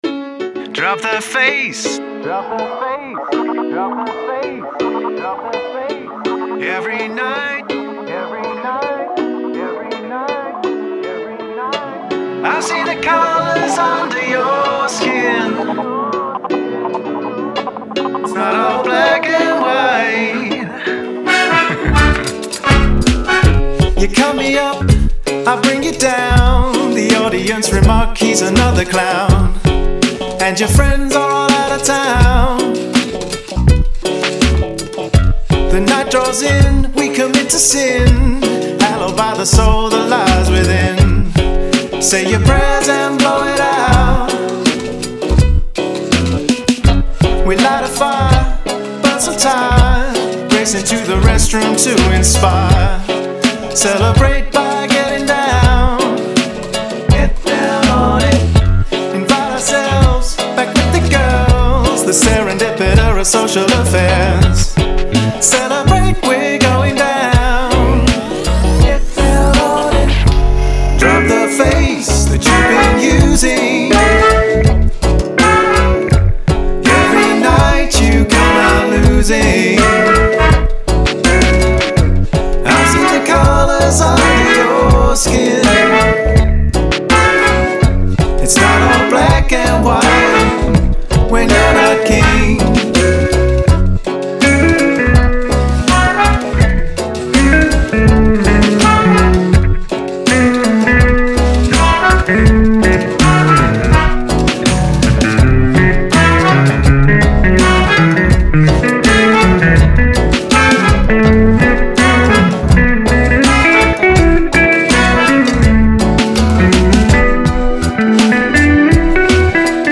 A bombasstic but nicely plodding lovers rock number